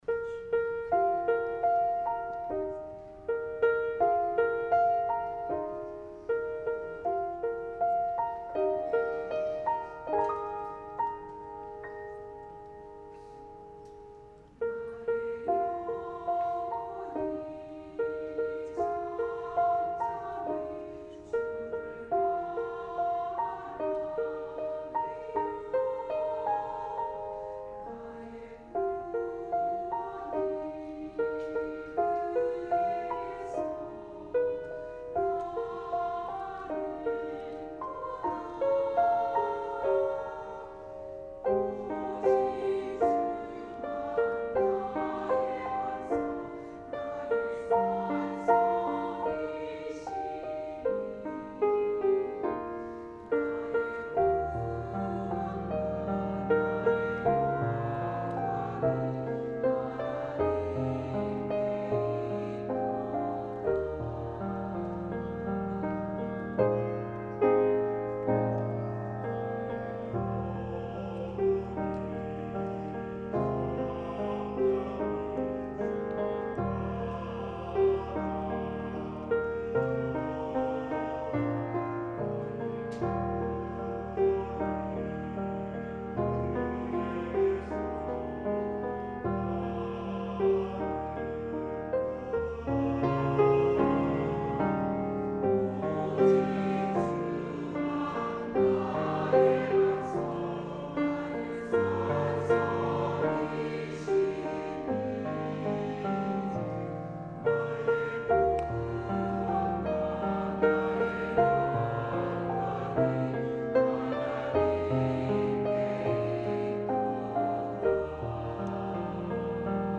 찬양 :: 나의 영혼이 잠잠히
나의 영혼이 잠잠히- 시온 찬양대 -